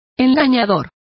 Complete with pronunciation of the translation of deceptive.